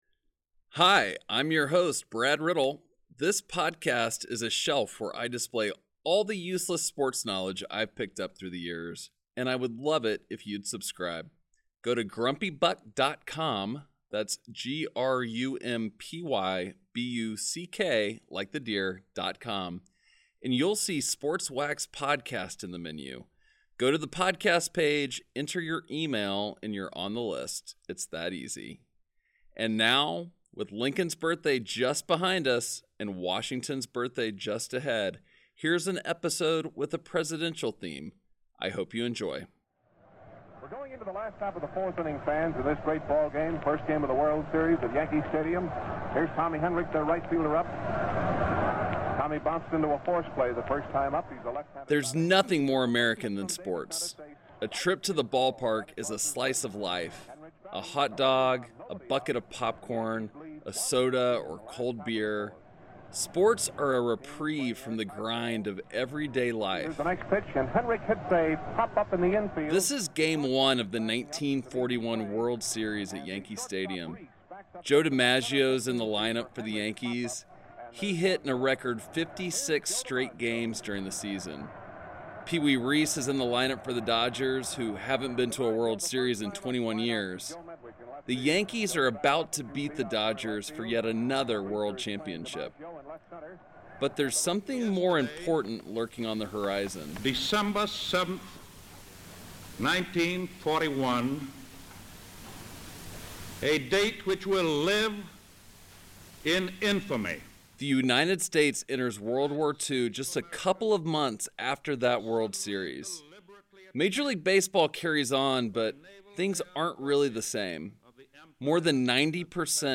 This episode, just after Lincoln's birthday and just before Washington's, is all about the relationship between presidents and our sports. Sports Wax is a weekly documentary that explores sports stories from yesteryear — memories of players, coaches, and teams that takes us back in time.